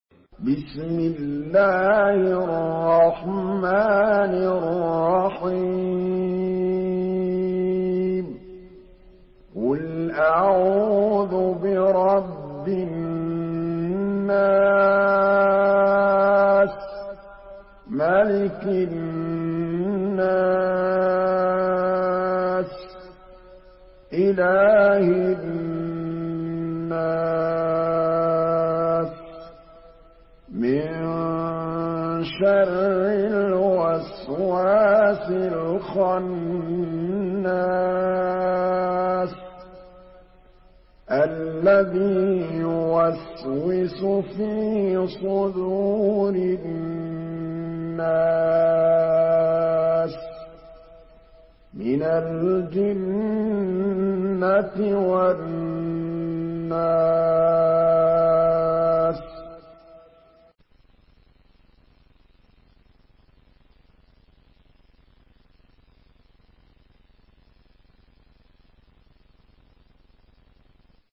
Surah An-Nas MP3 by Muhammad Mahmood Al Tablawi in Hafs An Asim narration.
Murattal